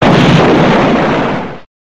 Play, download and share KADABOMMBA original sound button!!!!
bomb.mp3